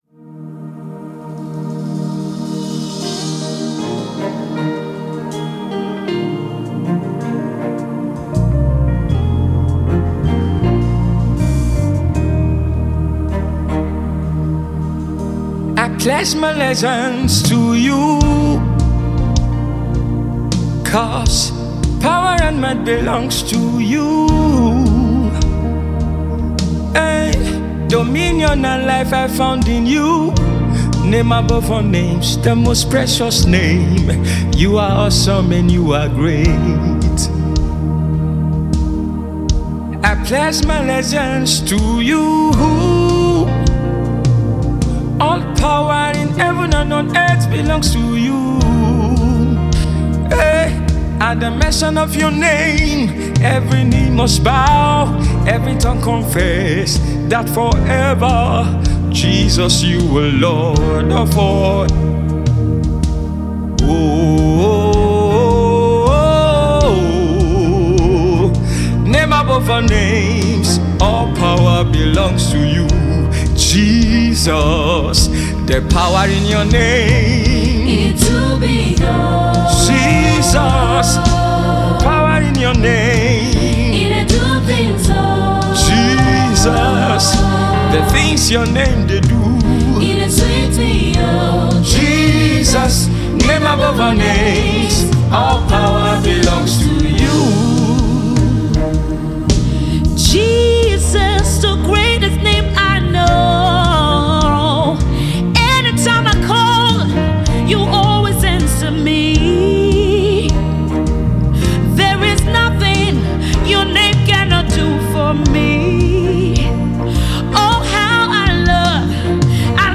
Pastor and Gospel music minister